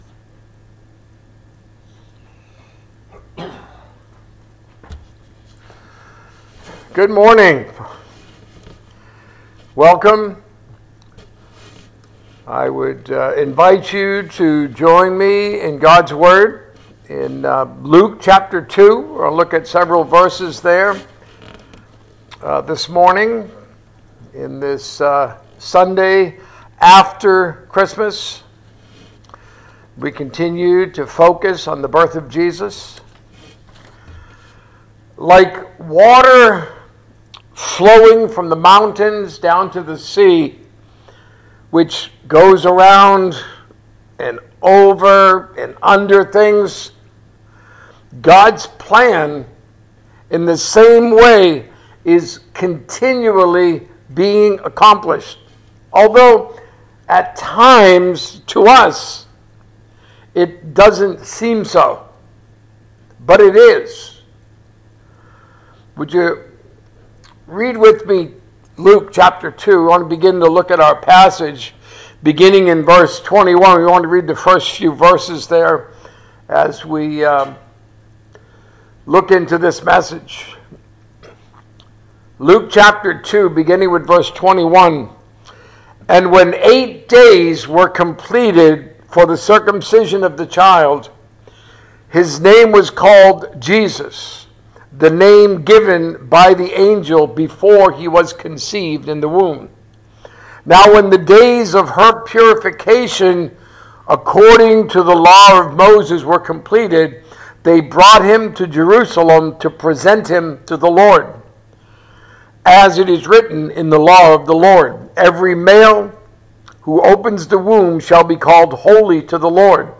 " A Message for Christmas " Sermons focused on the birth of our Savior Jesus Christ.